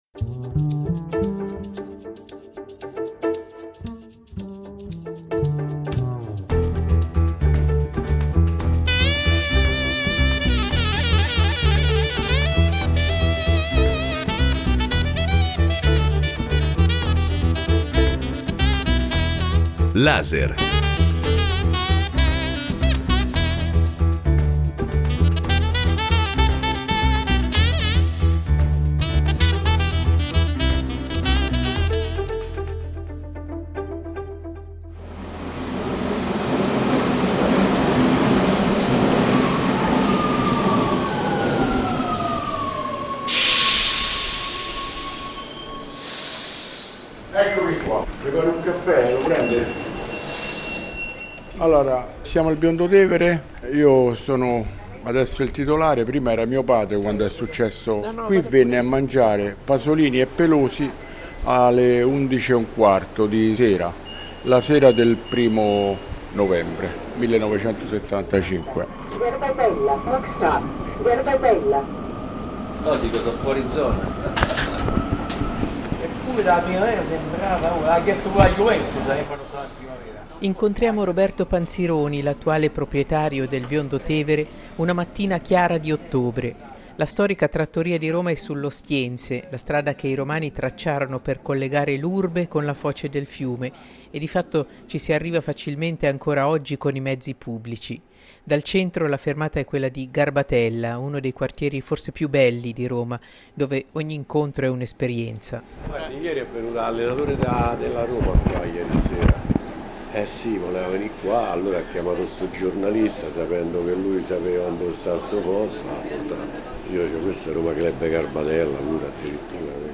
Reportage dalla trattoria Al Biondo Tevere, dove Pier Paolo Pasolini si fermò poche ore prima di essere assassinato, nel 1975